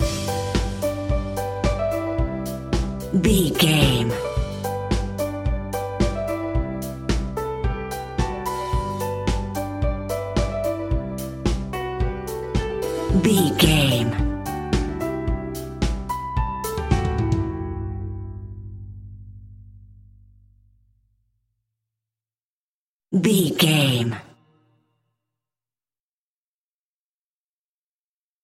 Motivation Business Pop Rock Music 15 Sec.
Ionian/Major
pop rock
indie pop
energetic
uplifting
instrumentals
upbeat
groovy
guitars
bass
drums
piano
organ